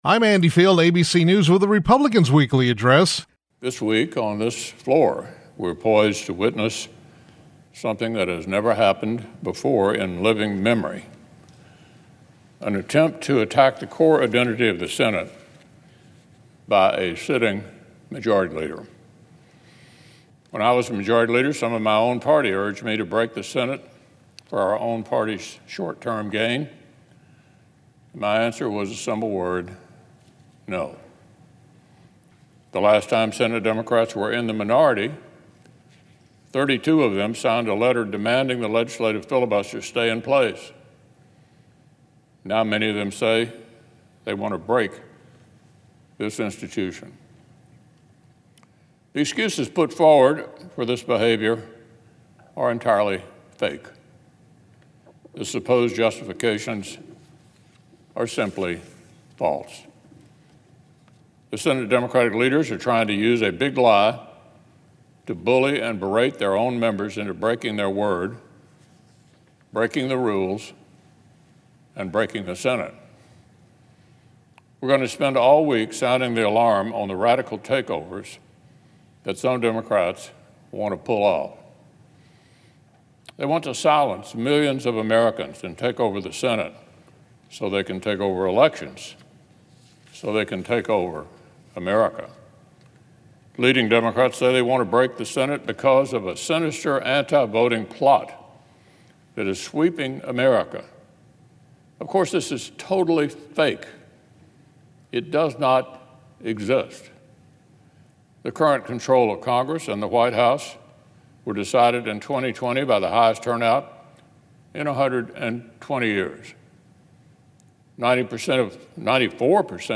On Monday, U.S. Senate Republican Leader Mitch McConnell (R-KY) delivered remarks on the Senate floor.